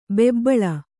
♪ bebbaḷa